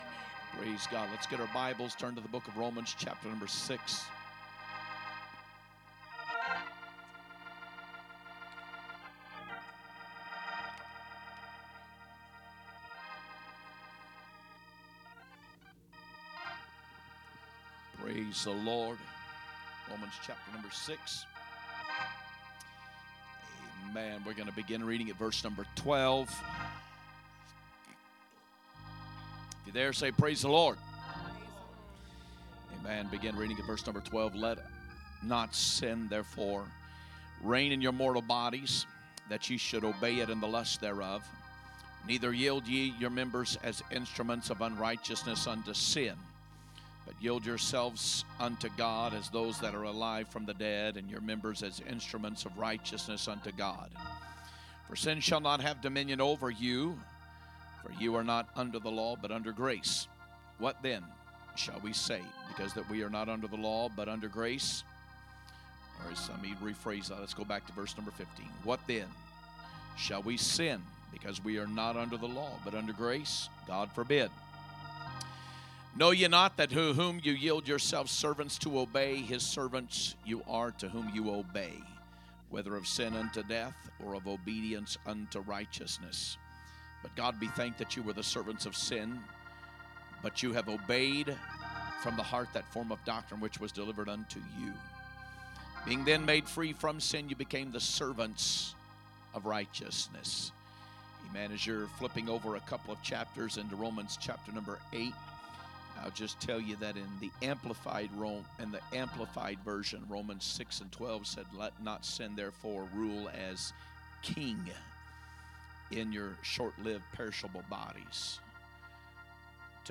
A message from the series "2026 Preaching." 3/15/2026 Sunday Evening Service
3/15/2026 Sunday Evening Service